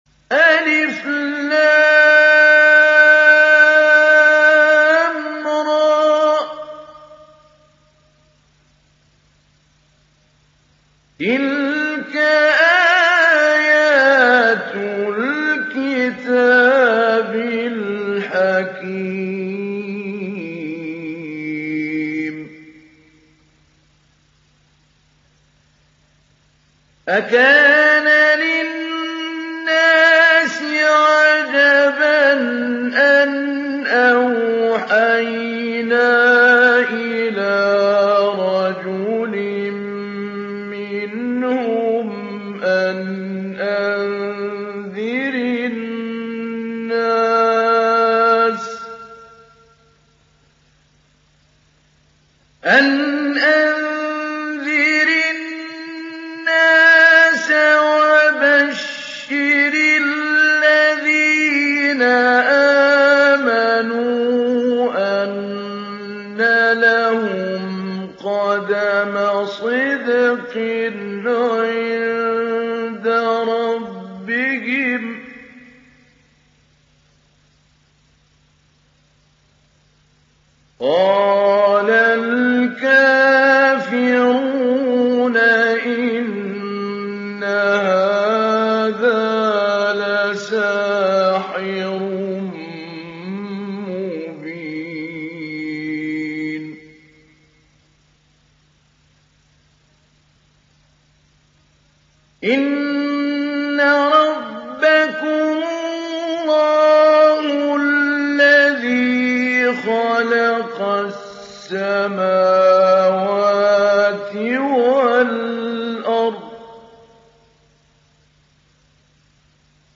Surah Yunus mp3 Download Mahmoud Ali Albanna Mujawwad (Riwayat Hafs)
Download Surah Yunus Mahmoud Ali Albanna Mujawwad